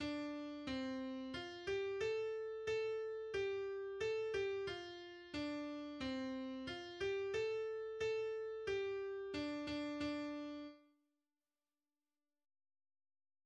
Műfaj magyar komolyzenei dal
Hangfaj pentaton
A kotta hangneme D moll